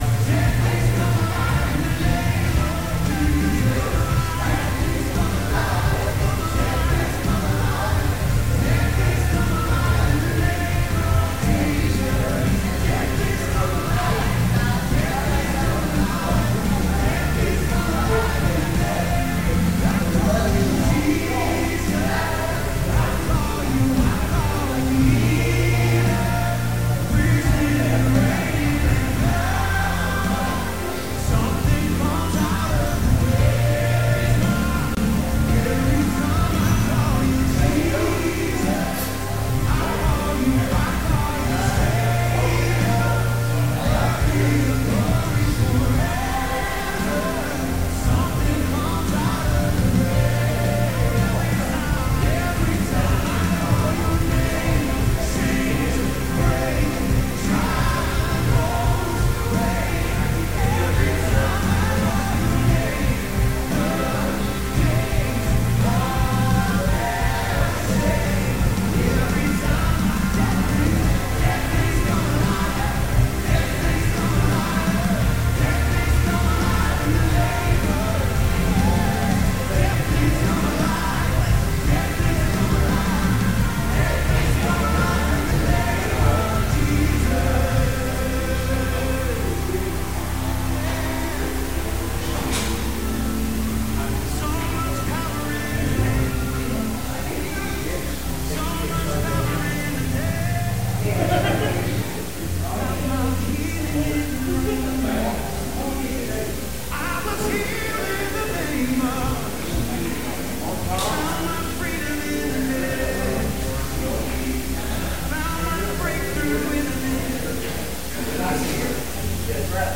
Sunday Morning Teaching